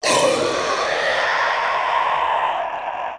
Faerie Dragon Yells
精灵龙叫声
Faeriedragonpissed1.mp3